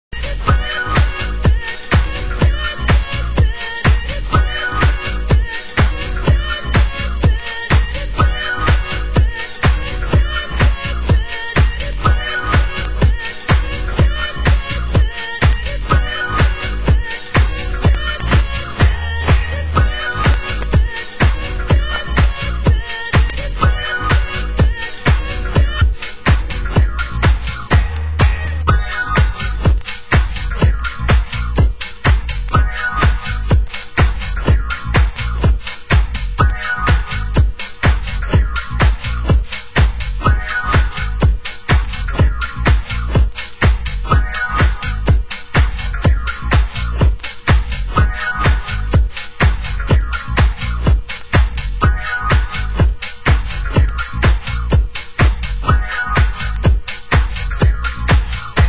Genre US House